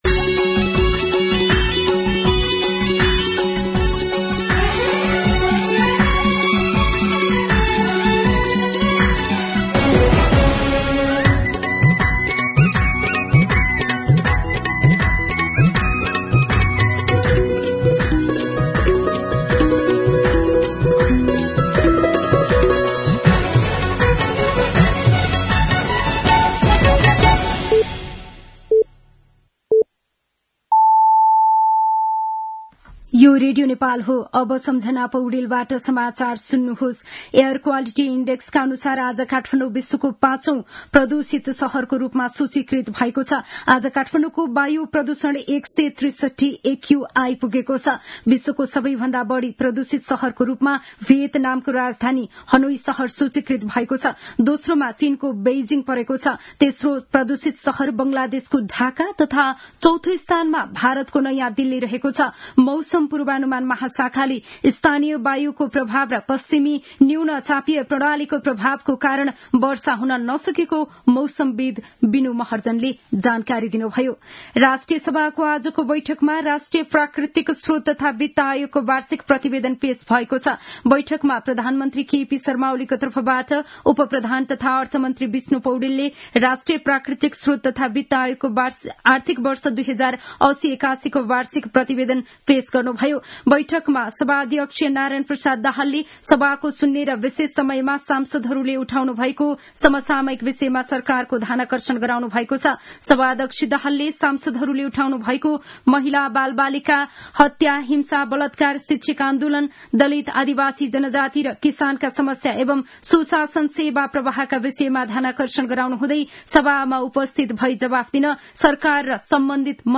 दिउँसो ४ बजेको नेपाली समाचार : २६ फागुन , २०८१